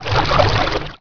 Here are some ripped soundFX from TR2 using Cool Edit Pro.